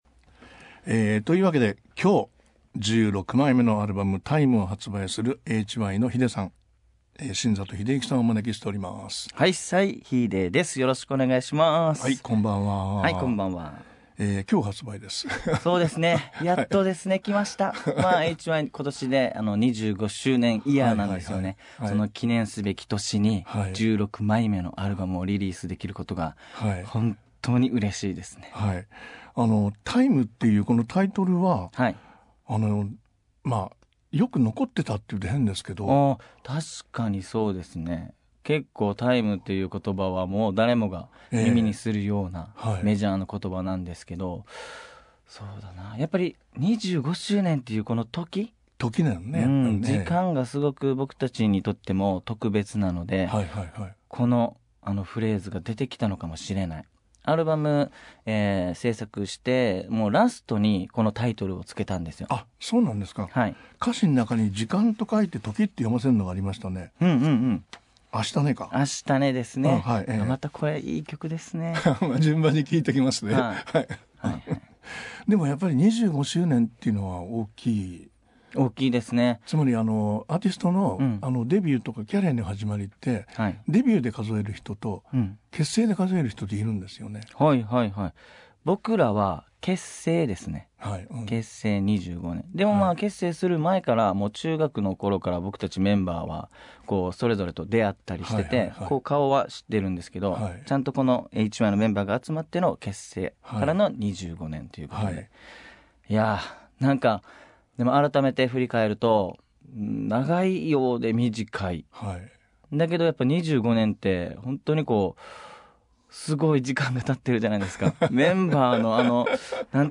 Podcastでは番組で放送した内容を「ほぼノーカット」でお送りしています。